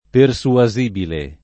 vai all'elenco alfabetico delle voci ingrandisci il carattere 100% rimpicciolisci il carattere stampa invia tramite posta elettronica codividi su Facebook persuasibile [ per S ua @& bile ] (meno com. persuadibile ) agg.